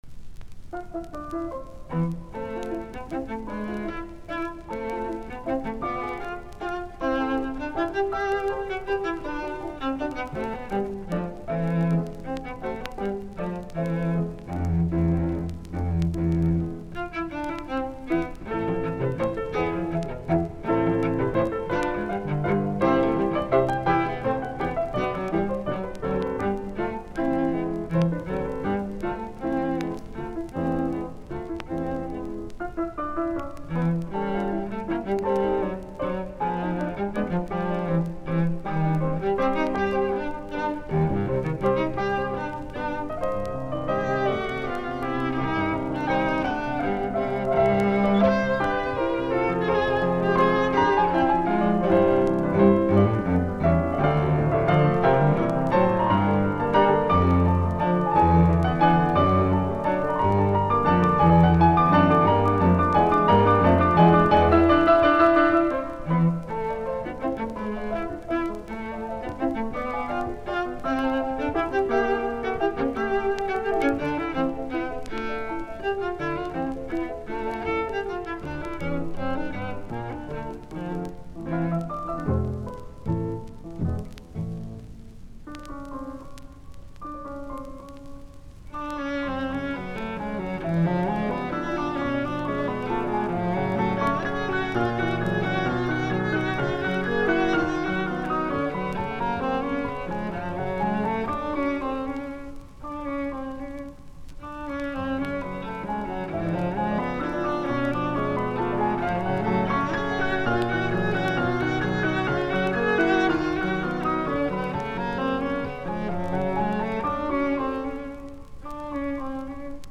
Soitinnus: Sello, piano.